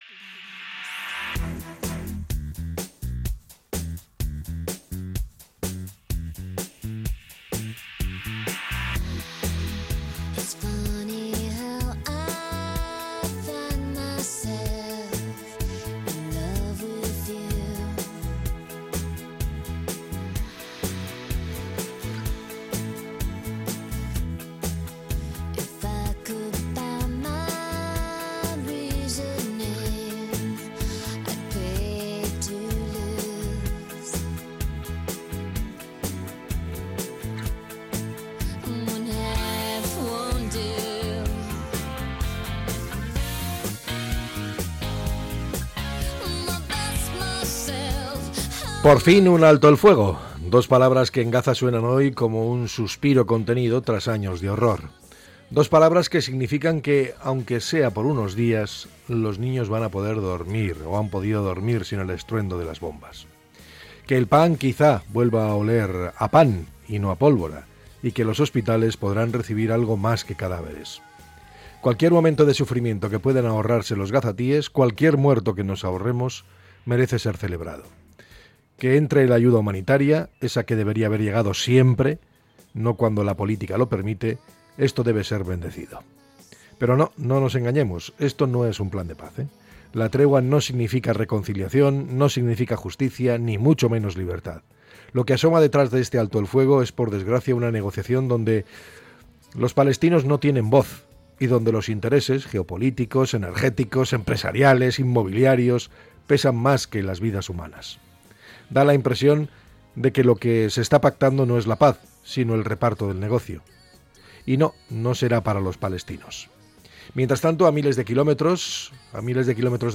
Podcast Opinión